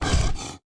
Npc Raccoon Run Sound Effect
npc-raccoon-run.mp3